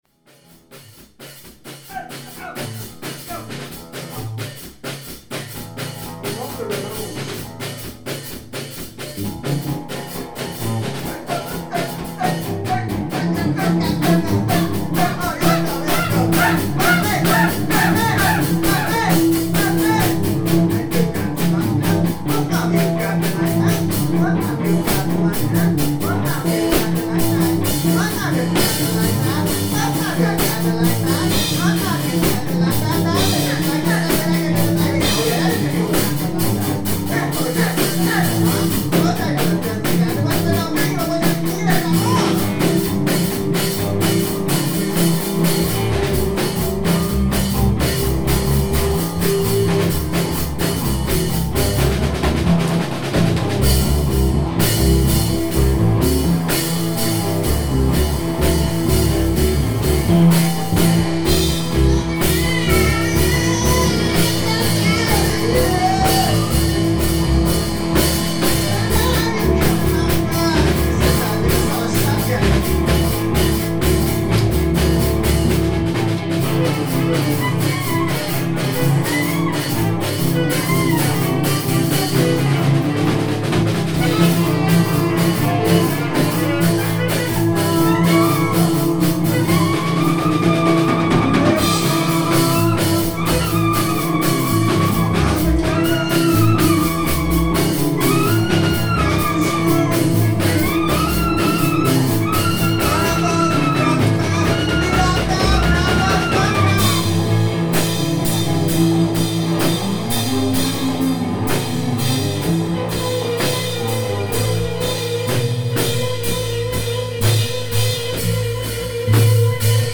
ALL MUSIC IS IMPROVISED ON SITE
moog/keys
alto sax
drums
bass